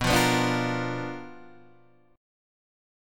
BmM7b5 chord {7 8 9 7 6 6} chord